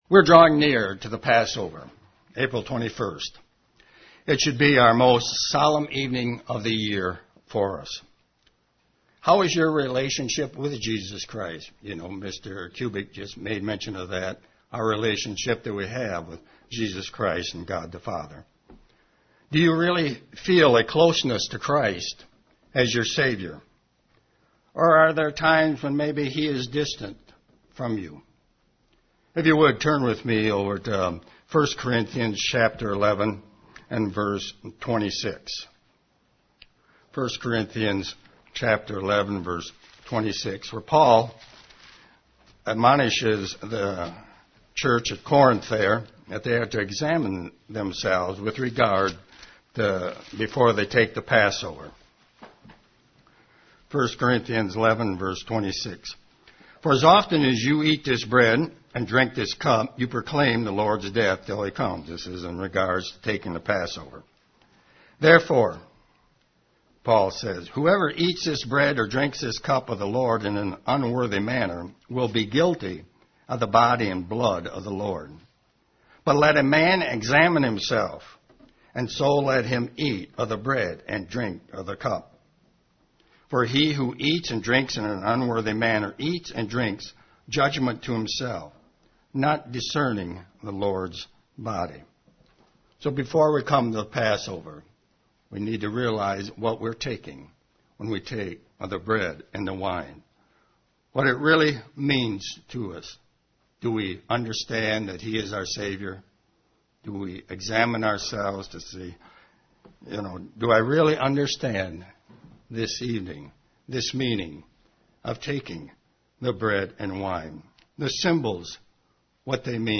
Given in Ann Arbor, MI